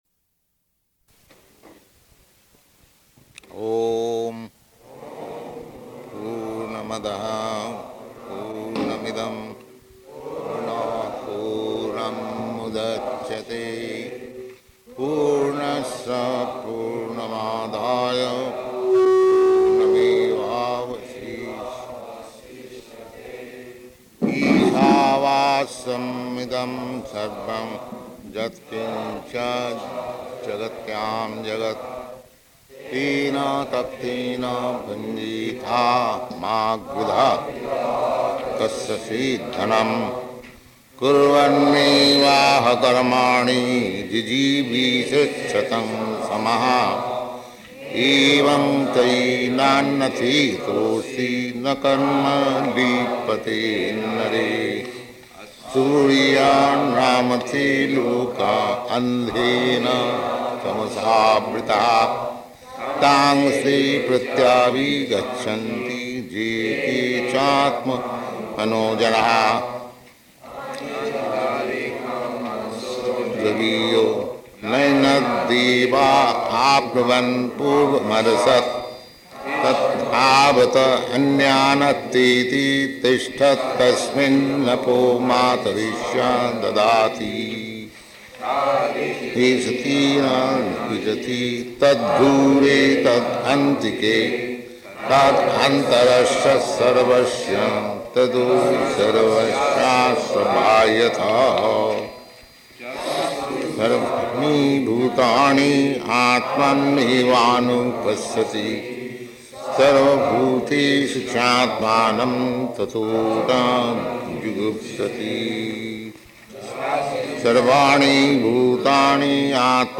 Location: Los Angeles